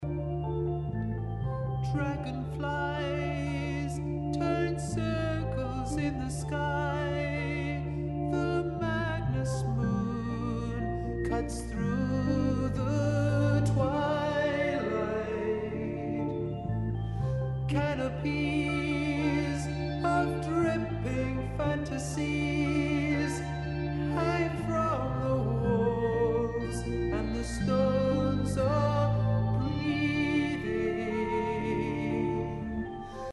Recorded at Command Studios, London